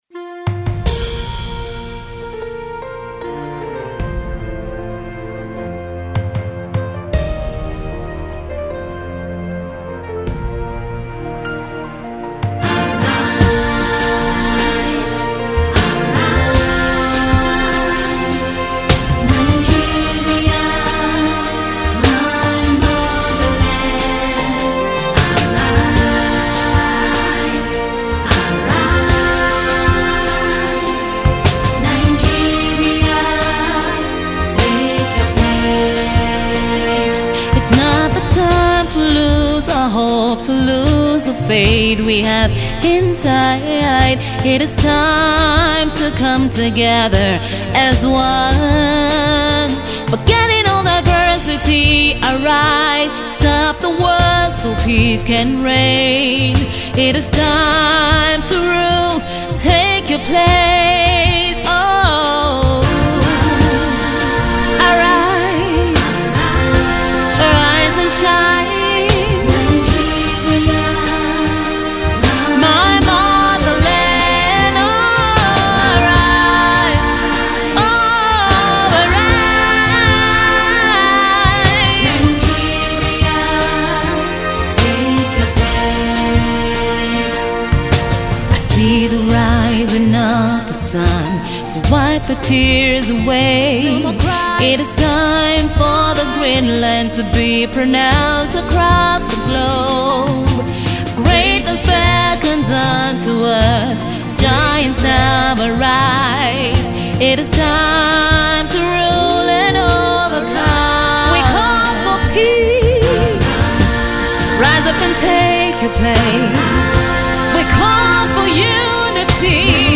Gospel/Inspirational Act
an Independence anthem